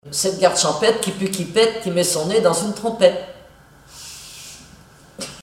formulette enfantine : amusette
comptines et formulettes enfantines
Pièce musicale inédite